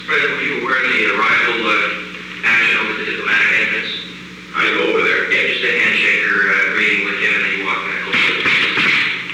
Secret White House Tapes
Location: Oval Office
The President met with an unknown man.